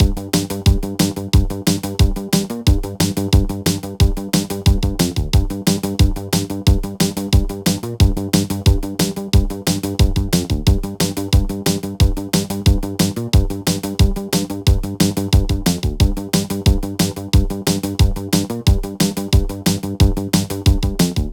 Bass Loop
bass_loop.ogg